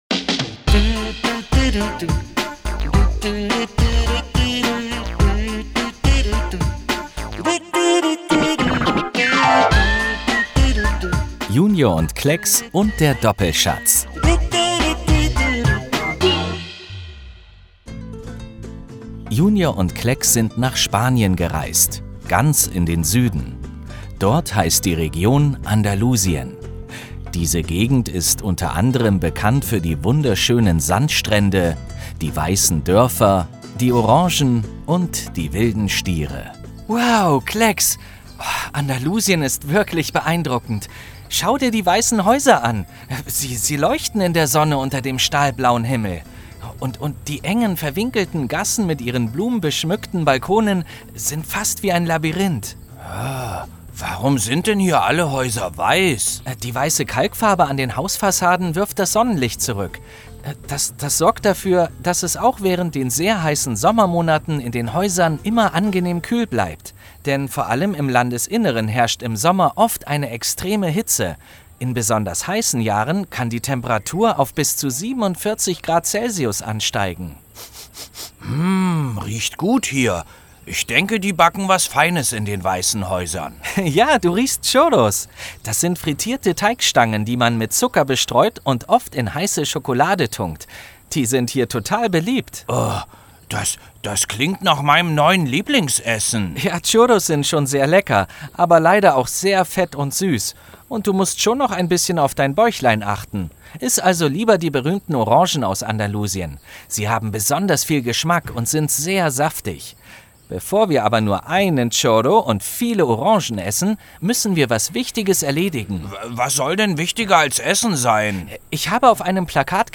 Hörspiel Junior & Klexx 25 05 - JUNIOR Schweiz